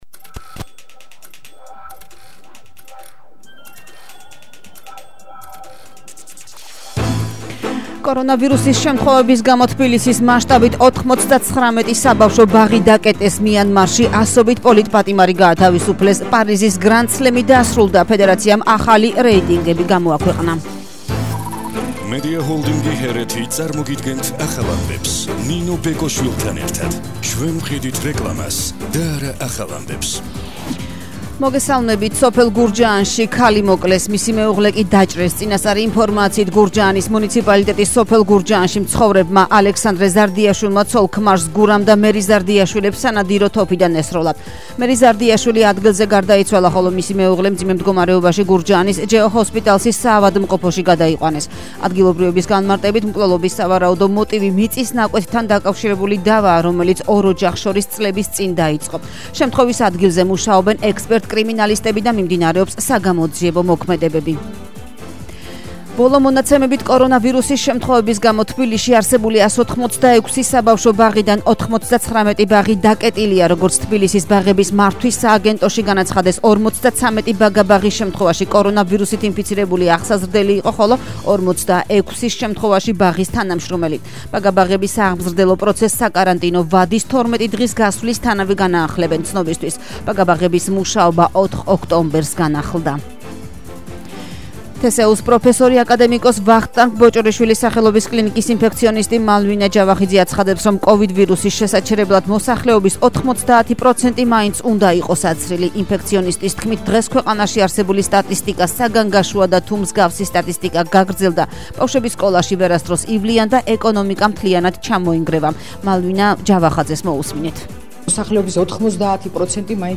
ახალი ამბები 13:00 საათზე –19/10/21